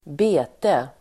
Uttal: [²b'e:te]